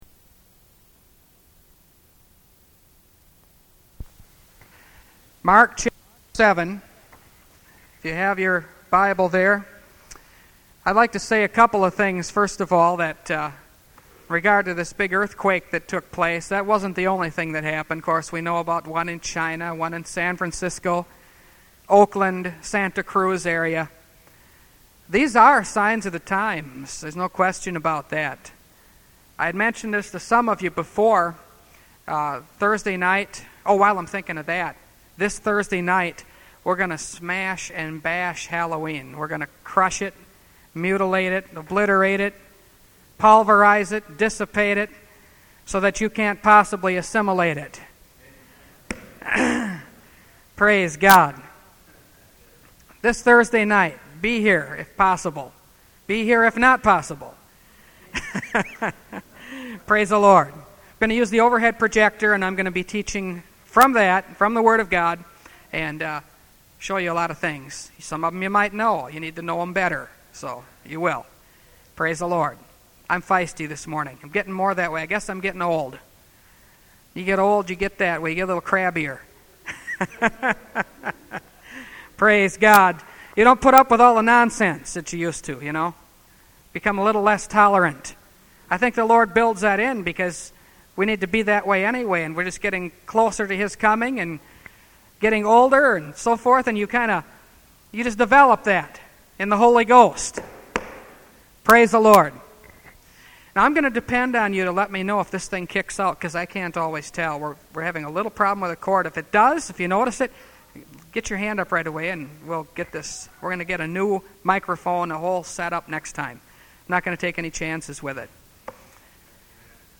Revelation Series – Part 22 – Last Trumpet Ministries – Truth Tabernacle – Sermon Library